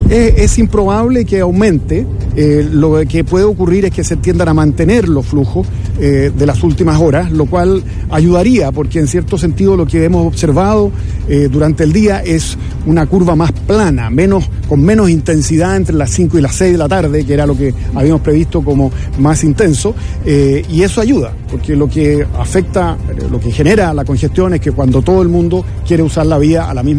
“Todavía está a tiempo de salir”, indicó el ministro ante la prensa esta tarde, tras lo cual volvió a recalcar la importancia de programar los viajes.